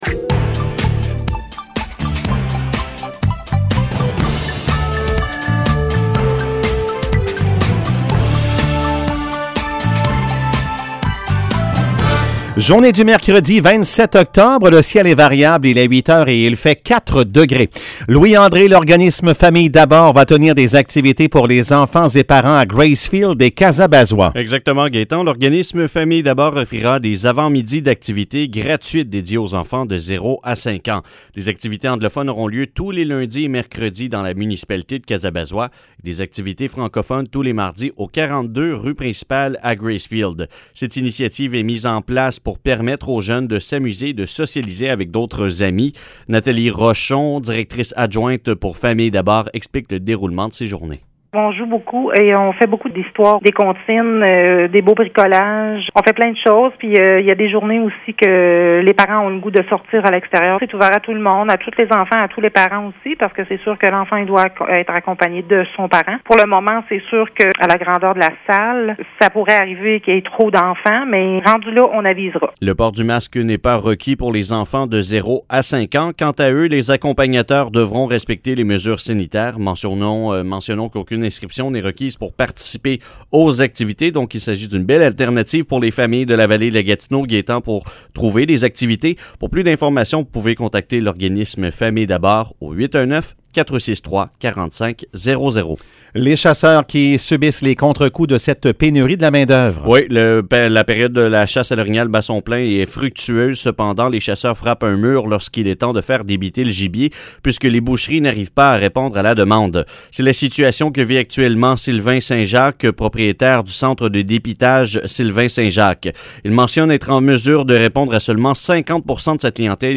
Nouvelles locales - 27 octobre 2021 - 8 h